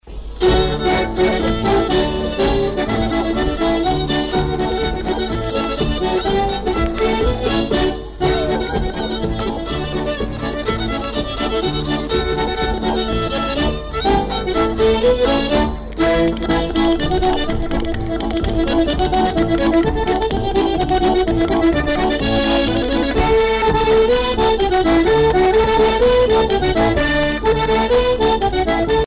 danse : bastringue
Pièce musicale éditée